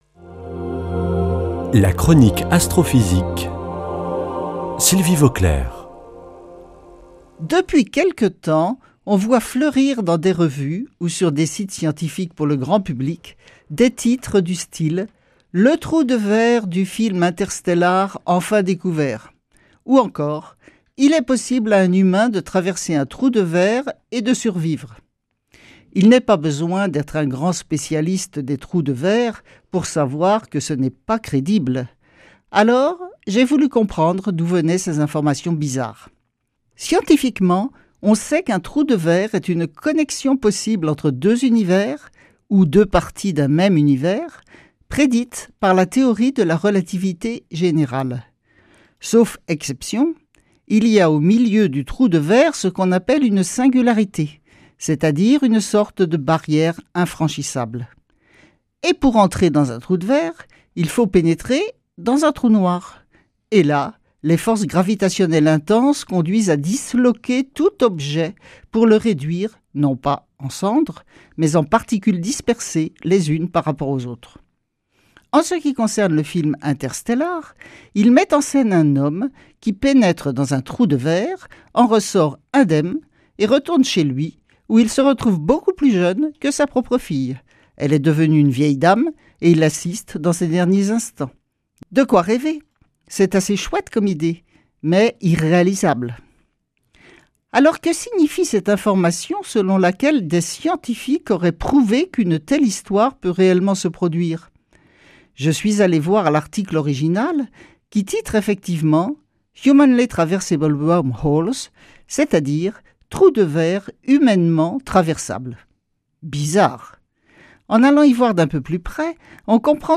Astrophysicienne